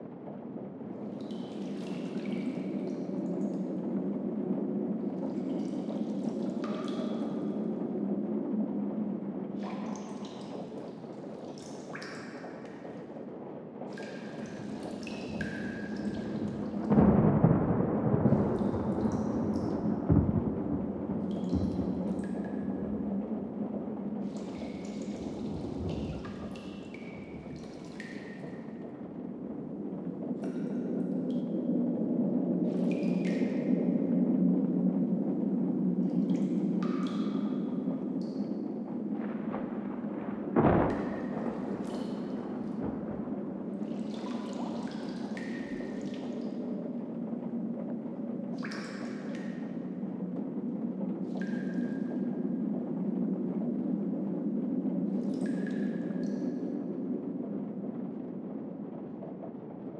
Cave Storm.ogg